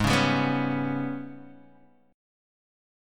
G#mM7bb5 chord